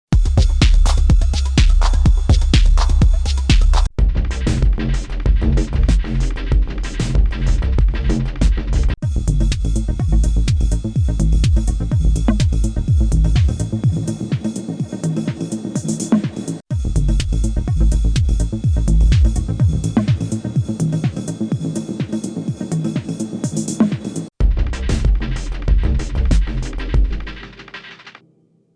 When changing to another Pattern with another Kit, there is a tiny silence during the change - even if the Kit settings are the same….
Here is a quick audio recording showing the Kit Change Silence issue.
It even happens on Kits which have the same settings.